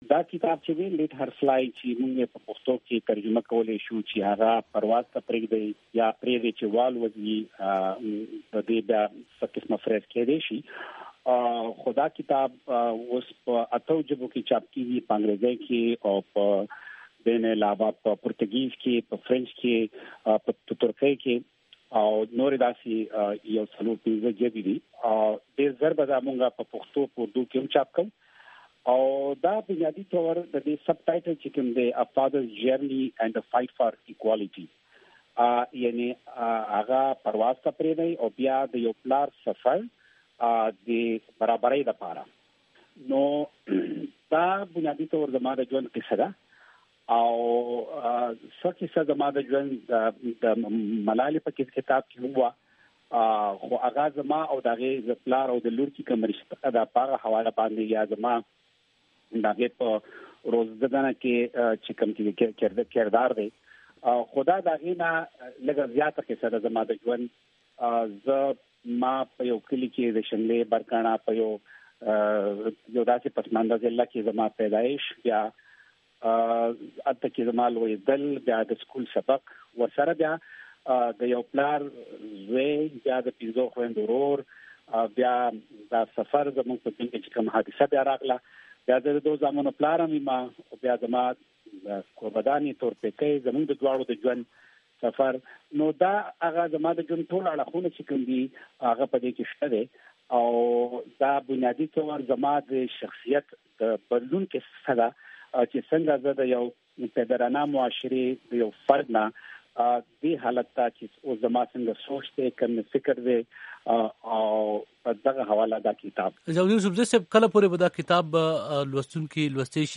د ضیالدين یوسفزي سره مرکه